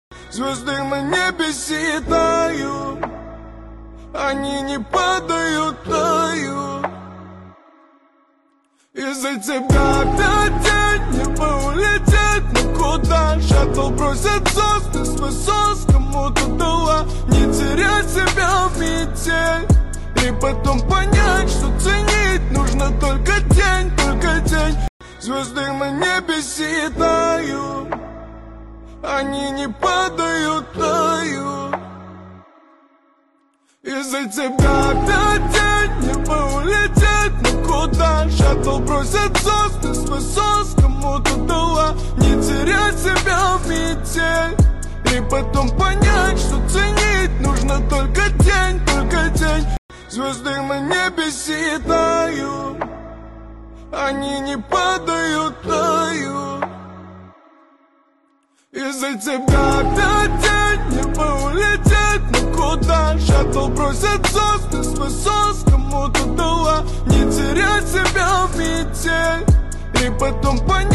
Русская музыка